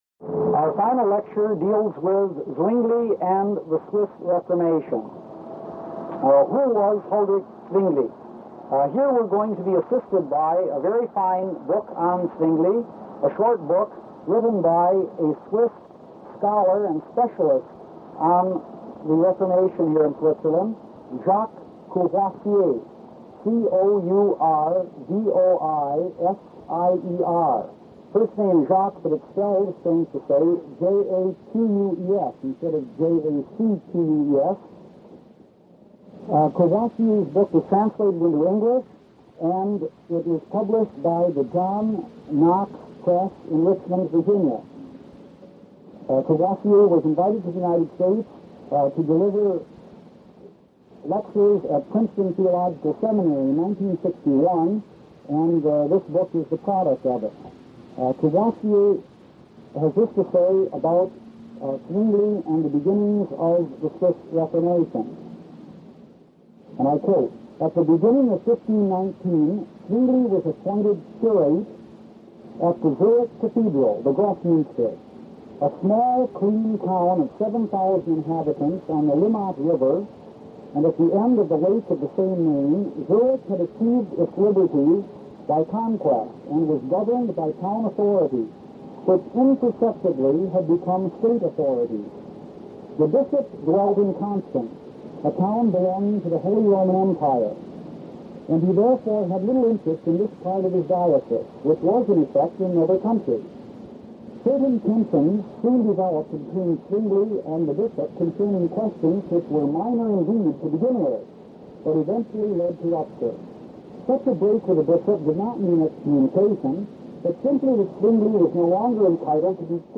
Reformation Studies: Issues And Challenges Audiobook